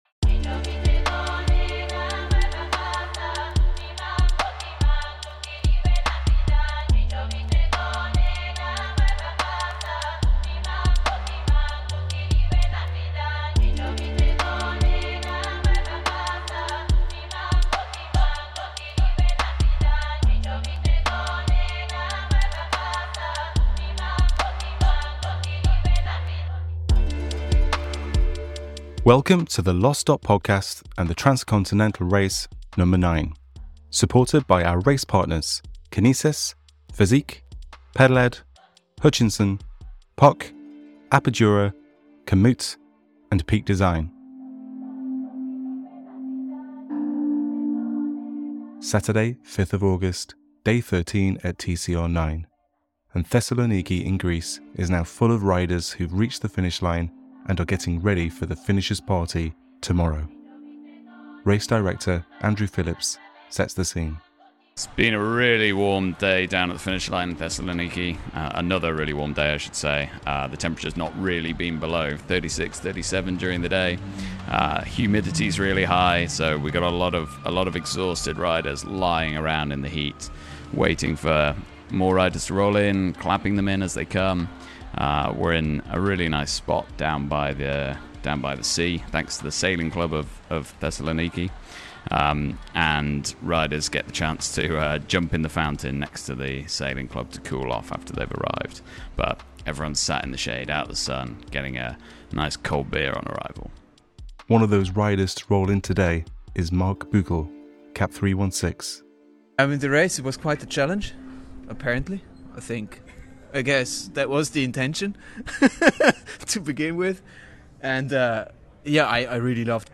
Catch up with riders fresh off the Finish line in Thessaloniki as the excitement is high and stories from the road are still raw. The Finish CP is now full of riders recounting their adventures as they get ready for the Finishes Party.